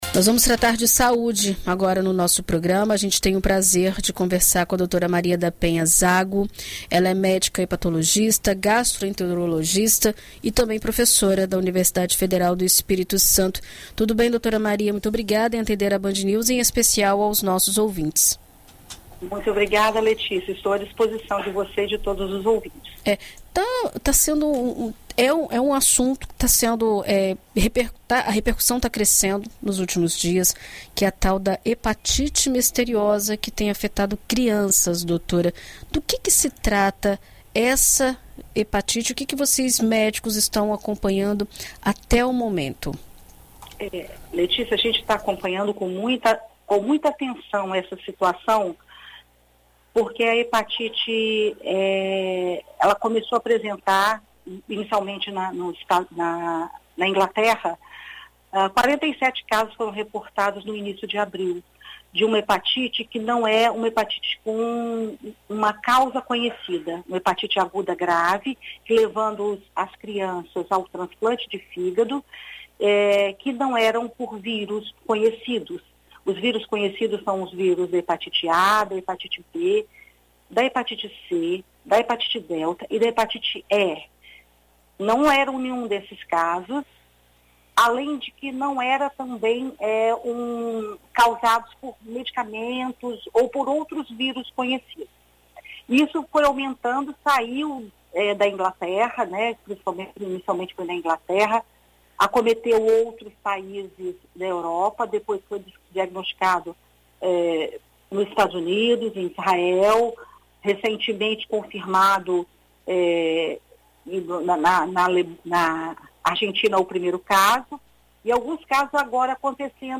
Em entrevista à BandNews FM Espírito Santo nesta terça-feira (10)